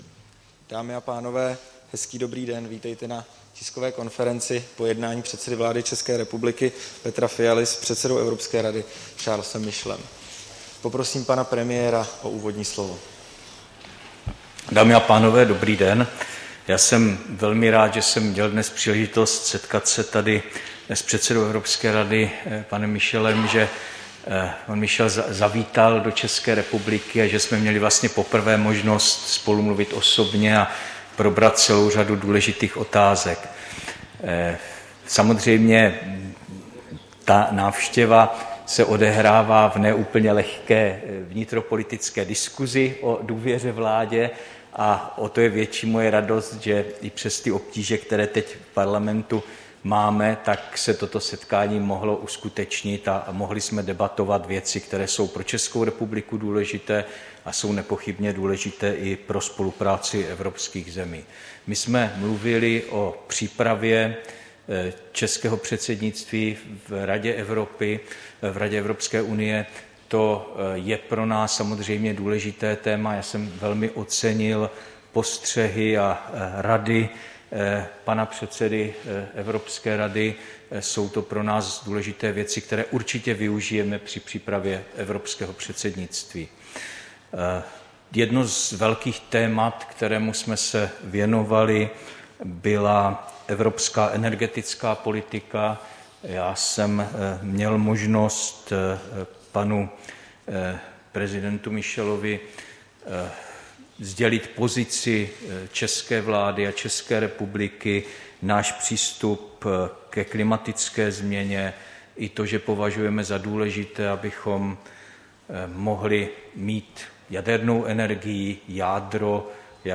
Tisková konference po jednání s předsedou Evropské rady Charlesem Michelem, 13. ledna 2022